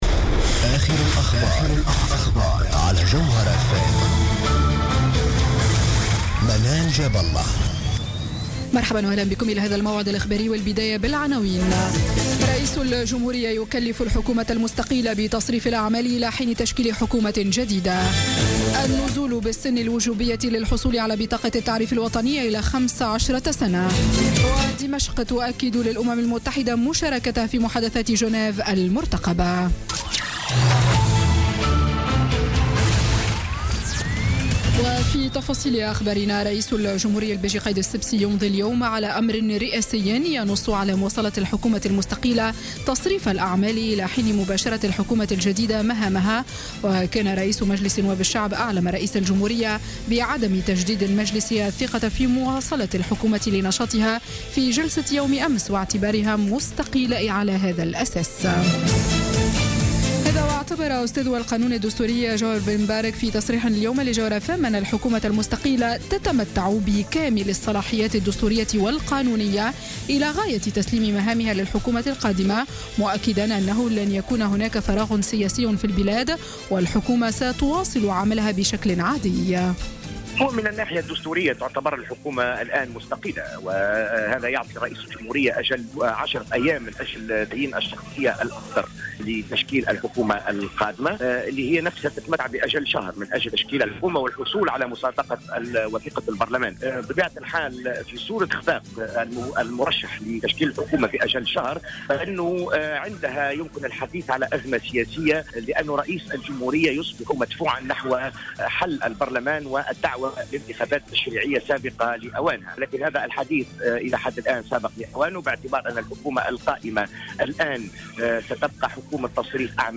نشرة أخبار السابعة مساء ليوم الـأحد 31 جويلية 2016